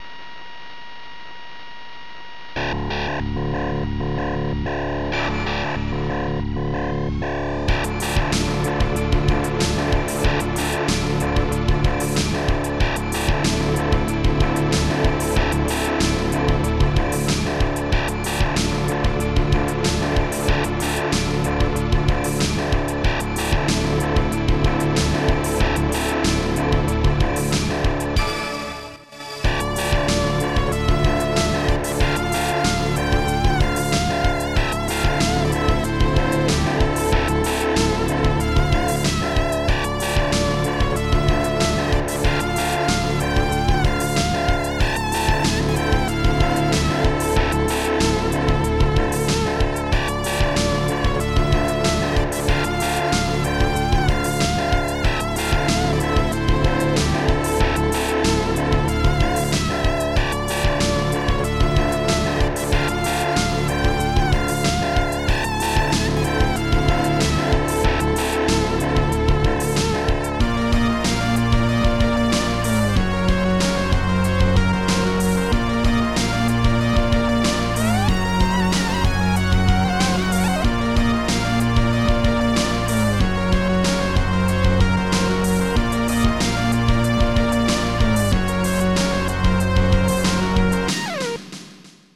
Protracker Module  |  2000-10-02  |  134KB  |  2 channels  |  44,100 sample rate  |  1 minute, 32 seconds
Protracker and family
ST-00:bass3
ST-00:snare3
ST-24:shadehihat
ST-24:synthbazz
string